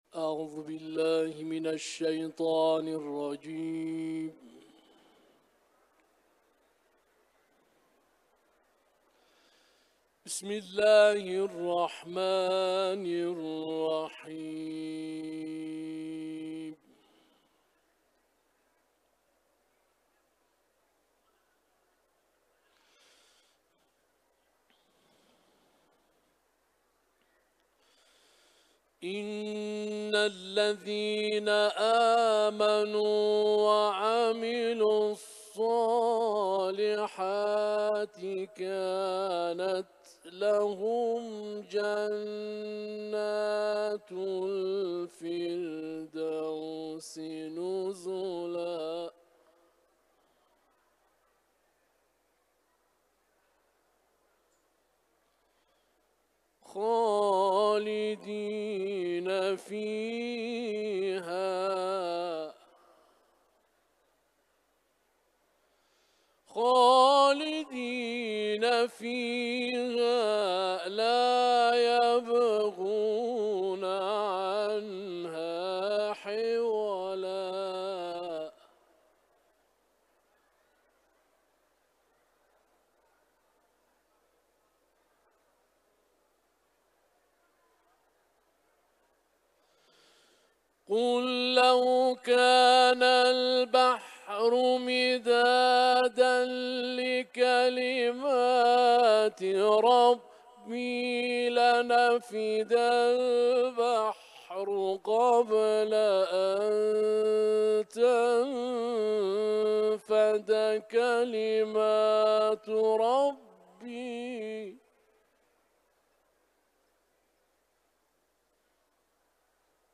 IQNA – Uluslararası Kur’an kârisi Kehf suresinden ayetler tilavet etti.